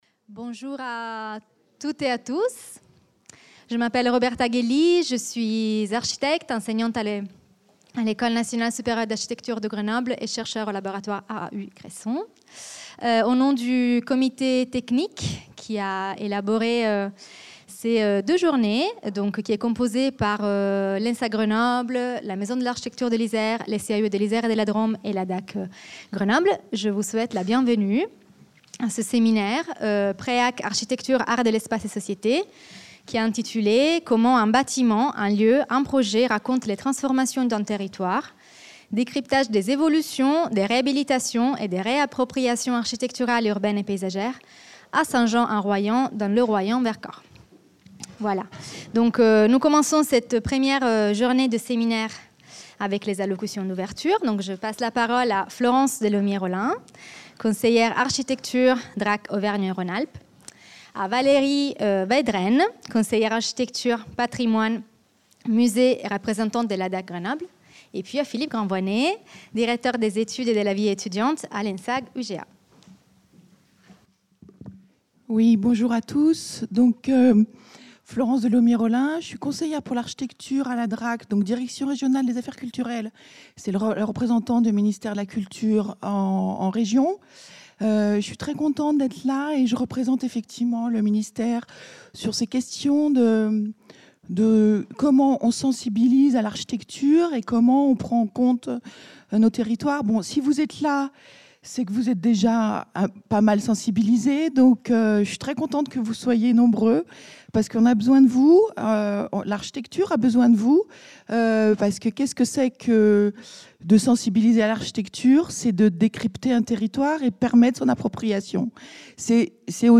Interviews
Séminaire du PREAC Architecture, arts de l’espace et société(s) à St Jean en Royans